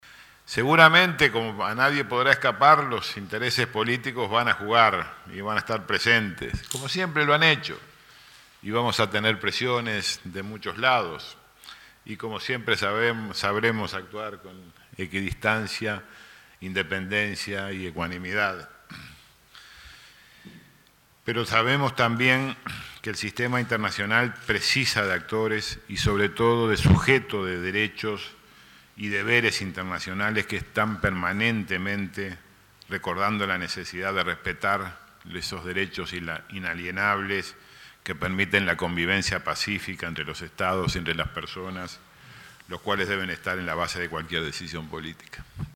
Interpelación en el Senado